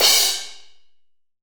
Index of /90_sSampleCDs/AKAI S6000 CD-ROM - Volume 3/Crash_Cymbal2/SHORT_DECAY_CYMBAL